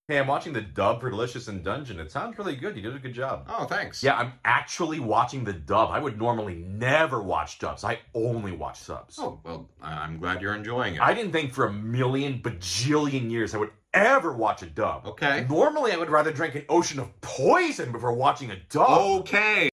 Talking_Male_Asian.wav